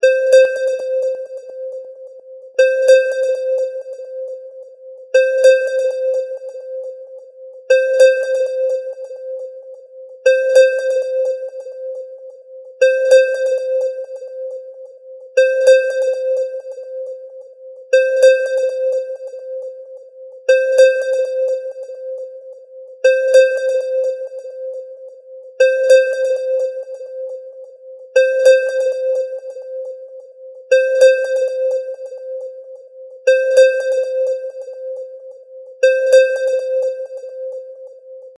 潜水艦のアクティブソナー風着信音です。